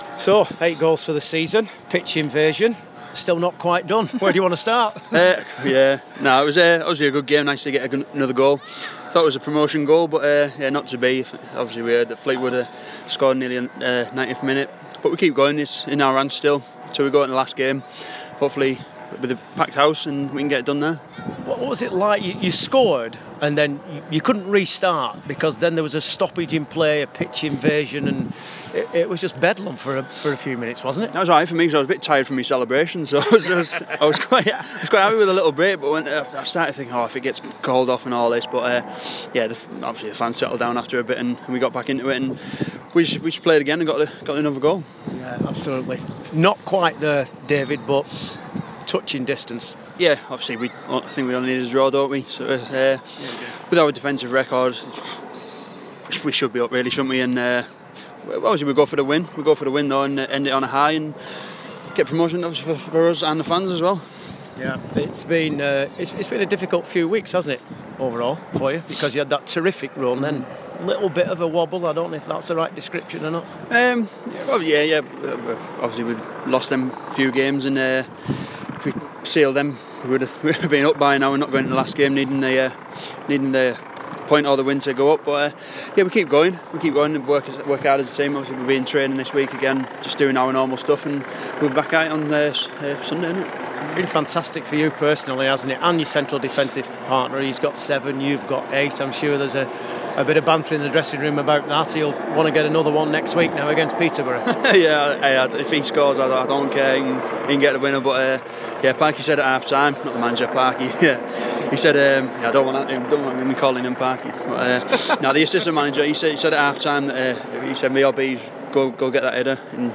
Bolton defender David Wheater talks following the vital win away to Port Vale.